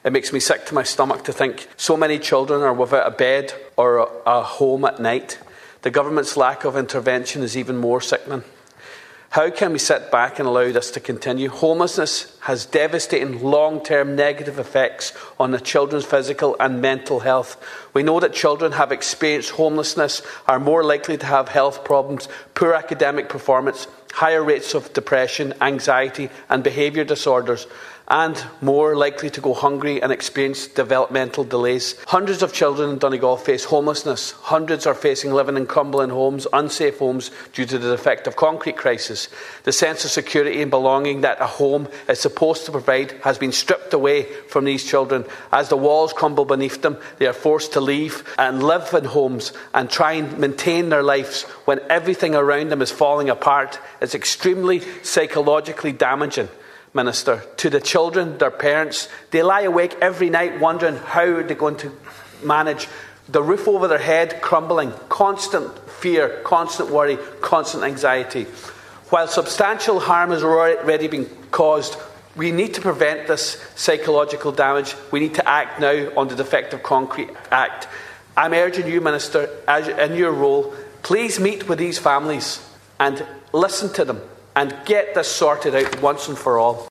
Speaking in the Dáil on a motion regarding Child Poverty and Homelessness, Deputy Charles Ward said hundreds of children in Donegal face homelessness, and hundreds more are living in crumbling, unsafe homes, due to the defective concrete crisis.